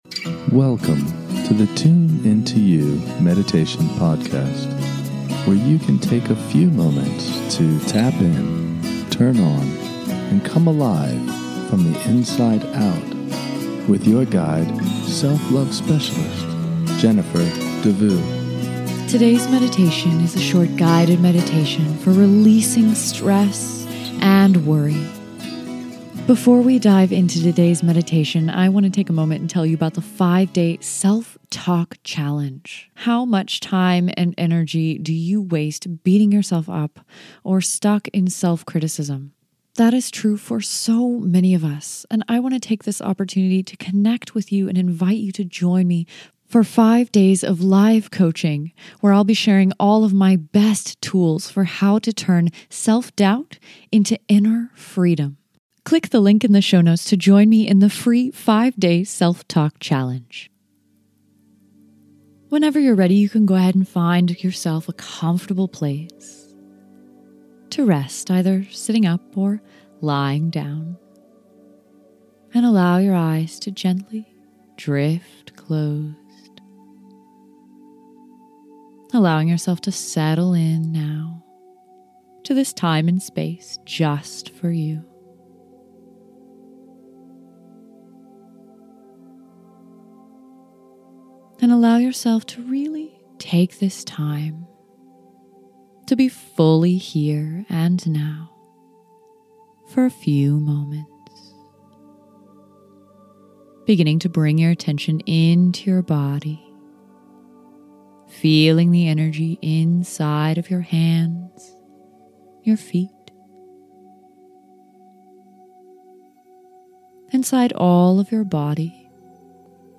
This is a short guided meditation for releasing stress and worried thoughts. In this meditation, you will be guided to release and let go of unproductive and worried thinking so that you can feel light and free.